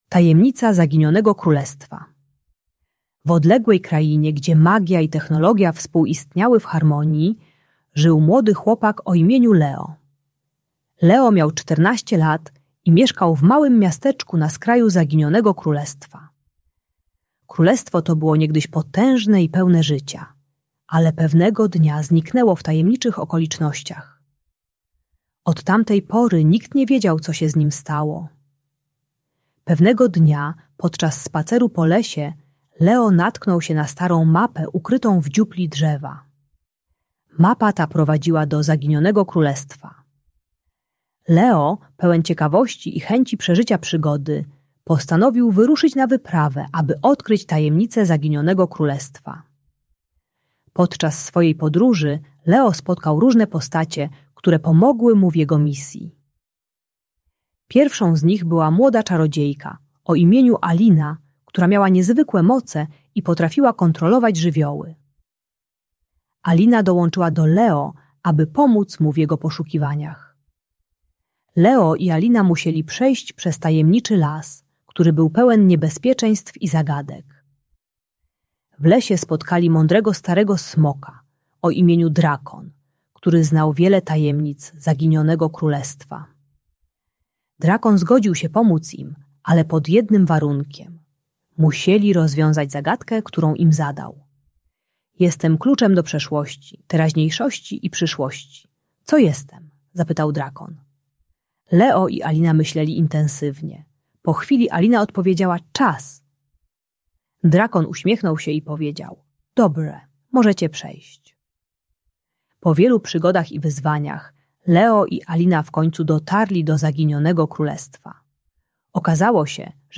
Tajemnica Zaginionego Królestwa - Bunt i wybuchy złości | Audiobajka